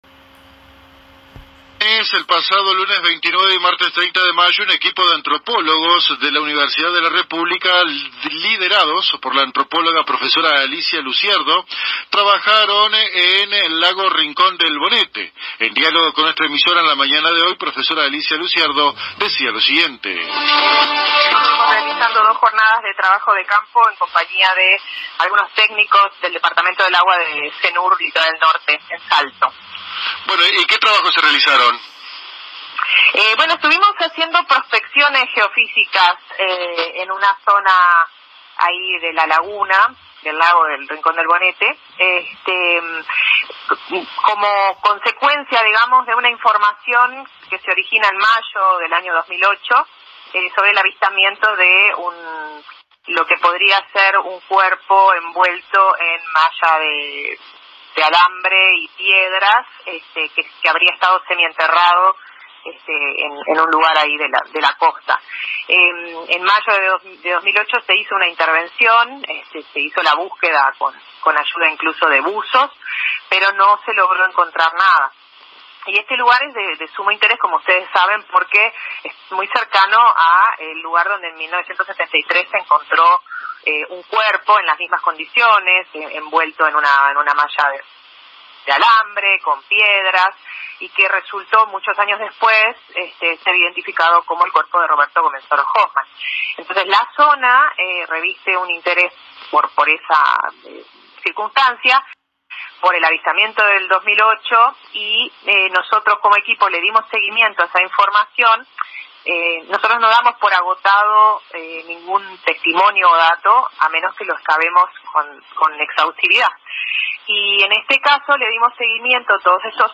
A pesar que trabajaron en un área de aproximadamente 70 mil km2 durante ambas jornadas, y utilizar equipamiento altamente especializado, los resultados fueron negativos. Las declaraciones de la profesional se dieron en entrevista con los colegas de la AM 1110 Radio Paso de los Toros.